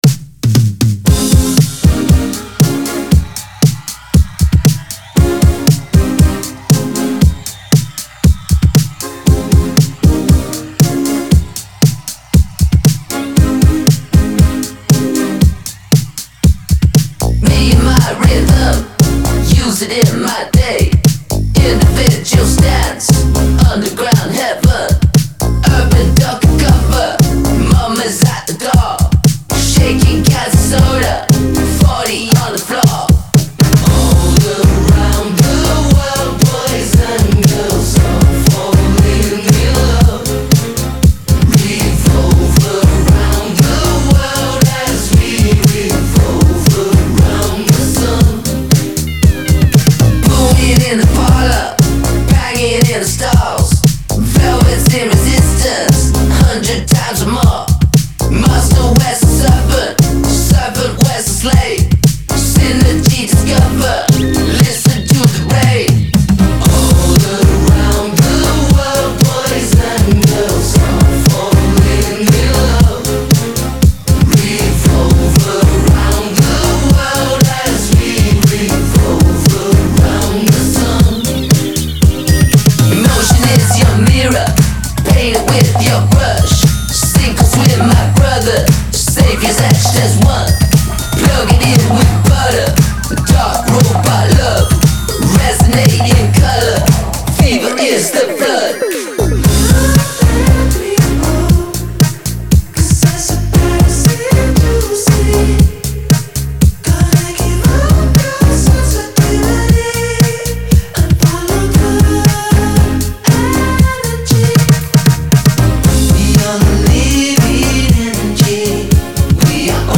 • Жанр: Pop, Electronic